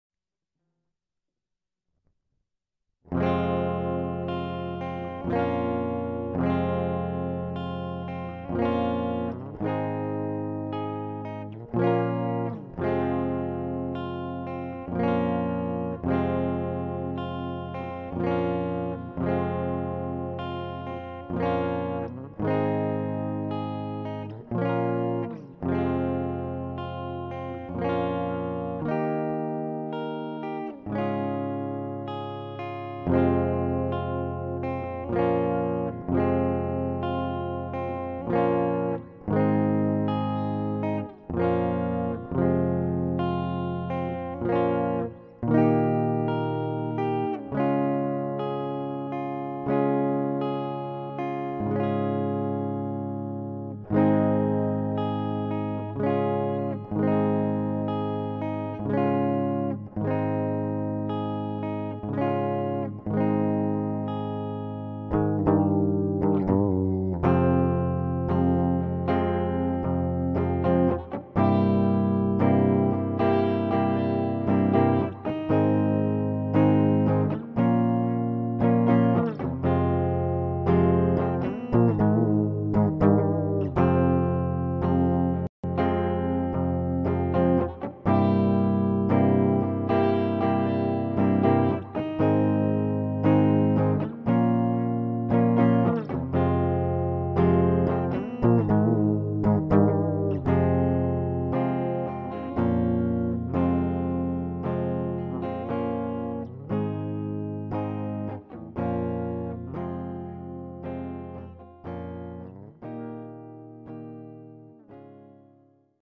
guitare4.mp3